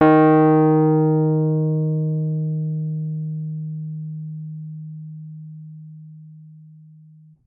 piano-sounds-dev
Rhodes_MK1